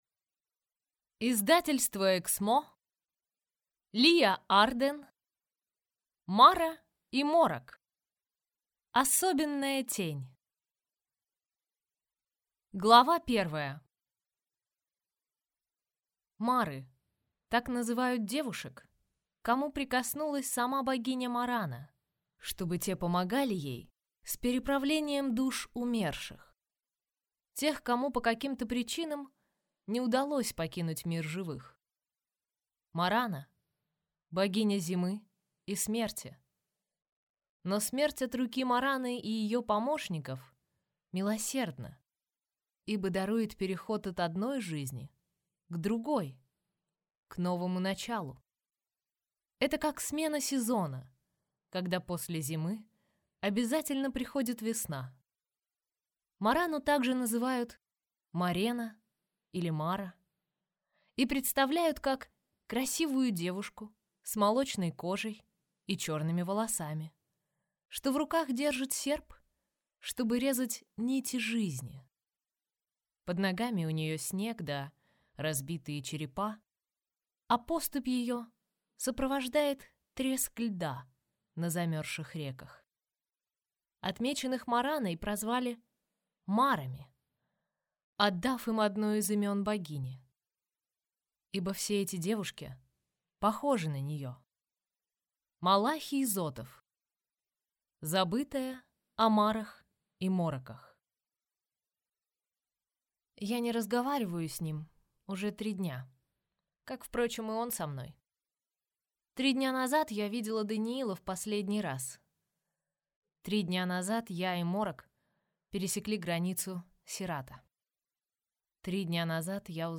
Аудиокнига Мара и Морок. Особенная Тень | Библиотека аудиокниг